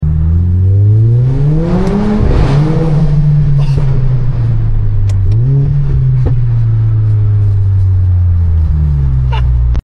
UNDERPASS LAUNCH IN A GLA45 sound effects free download